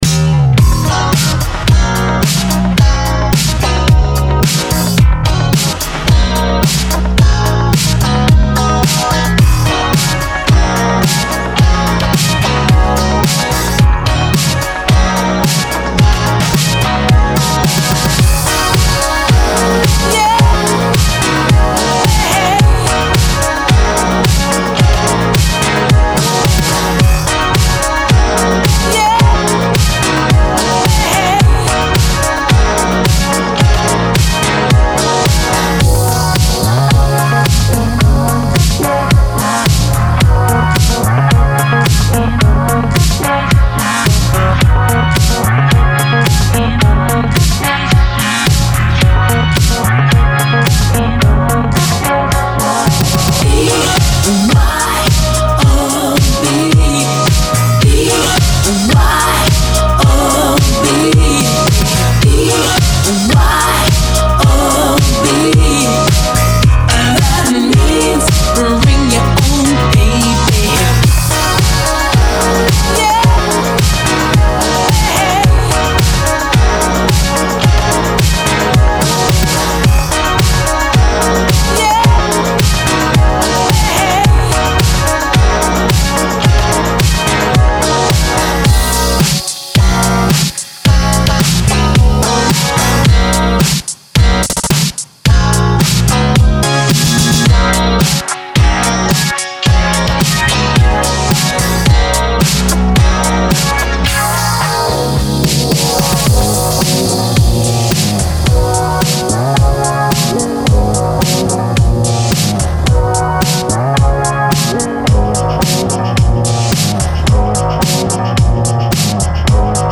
BPM109-109
Audio QualityPerfect (High Quality)
Future Funk song for StepMania, ITGmania, Project Outfox
Full Length Song (not arcade length cut)